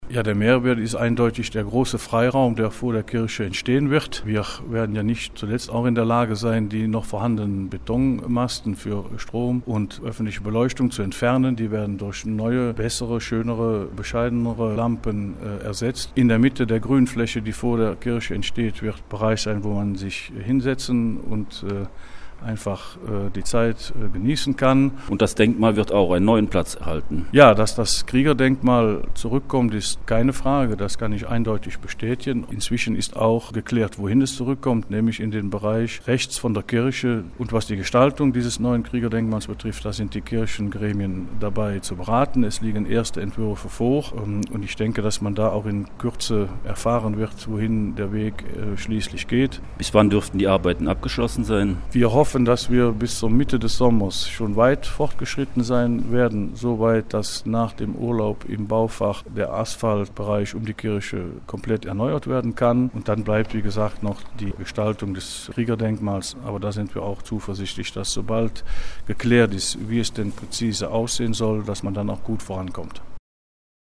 Bütgenbachs Bauschöffe zur Neugestaltung des Dorfes
Soweit Bauschöffe Charles Servaty zur Dorfverschönerung in Bütgenbach.